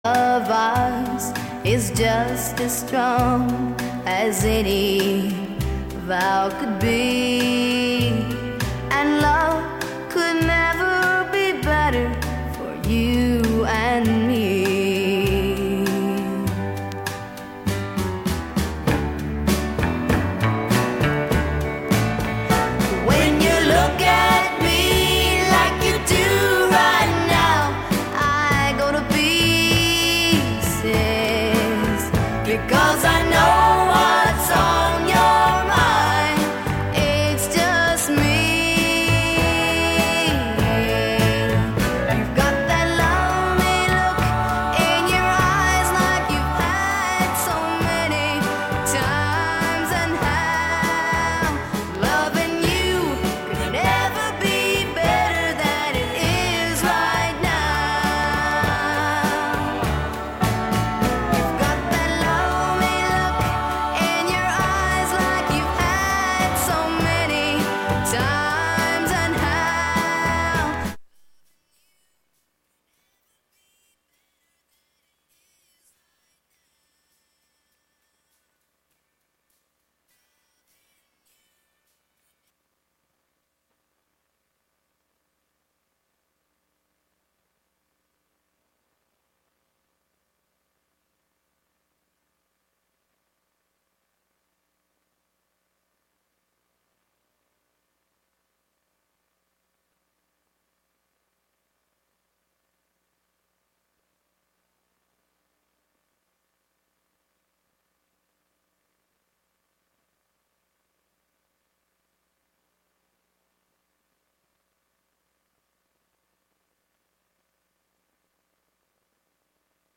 i play old scratchy records